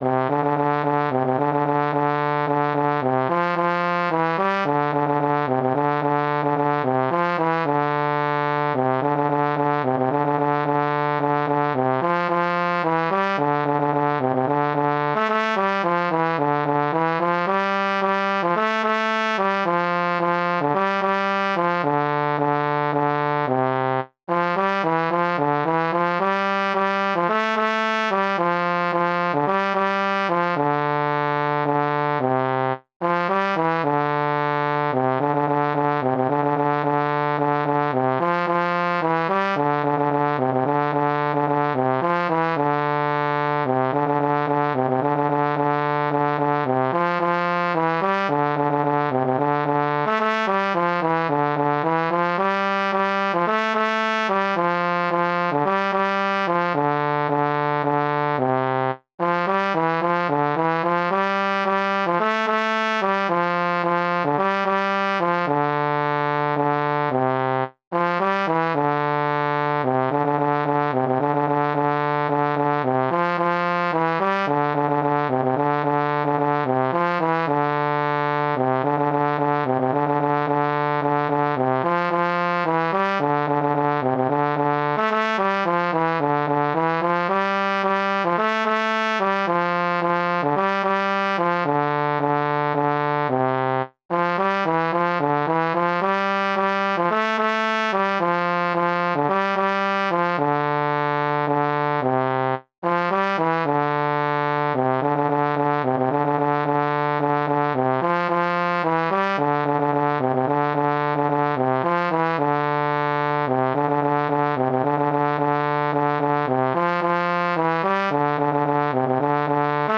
Jewish Folk Song
D minor ♩= 110 bpm